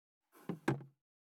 260,食器にスプーンを置く,ガラスがこすれあう擦れ合う音,カトラリーの音,食器の音,会食の音,食事の音,カチャン,コトン,効果音,環境音,BGM,カタン,チン,コテン,コン,